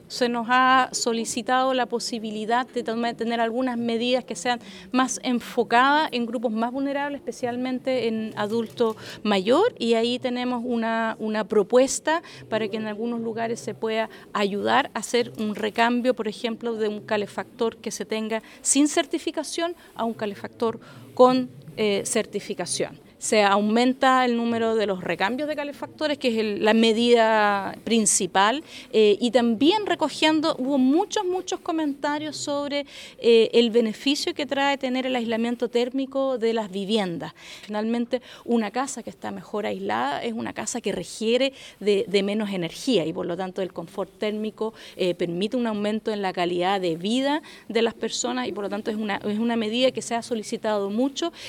Del mismo modo, la Ministra Rojas explicó que hay propuestas para avanzar en el recambio de calefactores de manera especial para adultos mayores; el beneficio del aislamiento térmico; entre otras medidas.